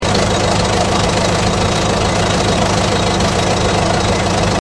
combustion.mp3